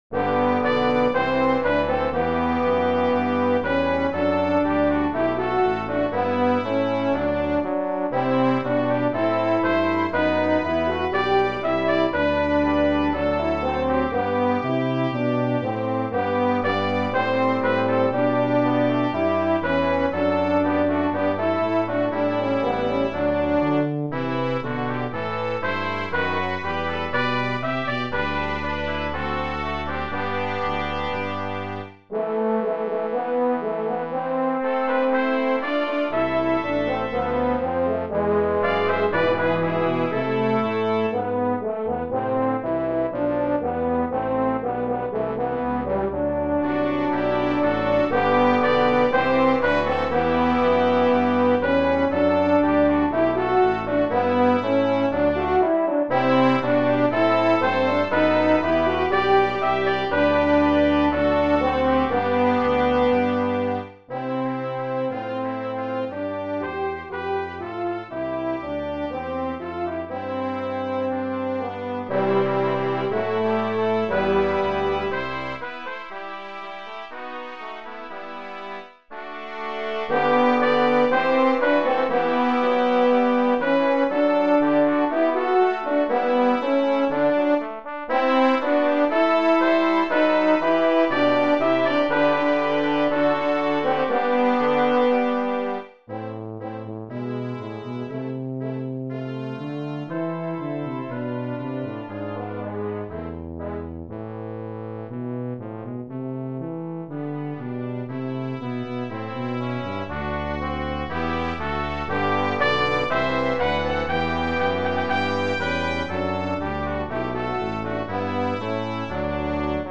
Brass Quintet
This is a very "church appropriate" quintet arrangement